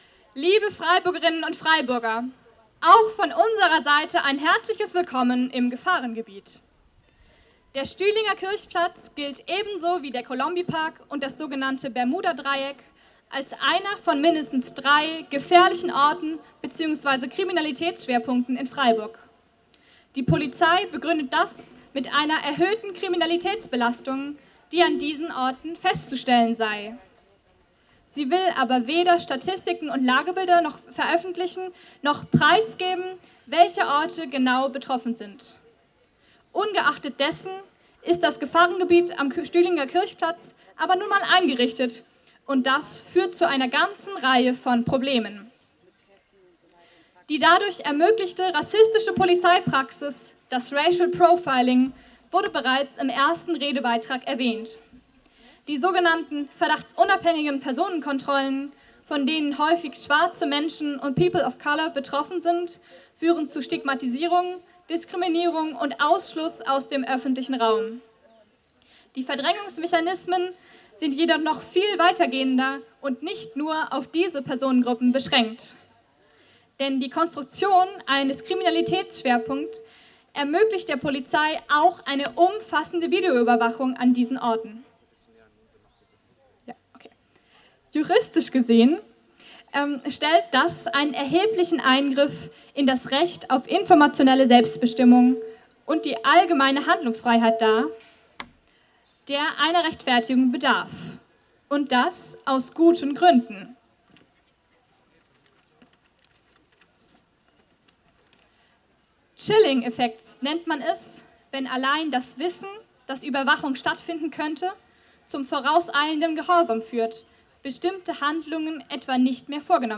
Am Samstag den 30.06.2018 fand auf dem Stühlinger Kirchplatz eine Kundgebung gegen Racial Profiling und für die Abschaffung sogenannter "Gefährlicher Orte" statt, zu denen auch der Stühlinger Kirchplatz selbst zählt.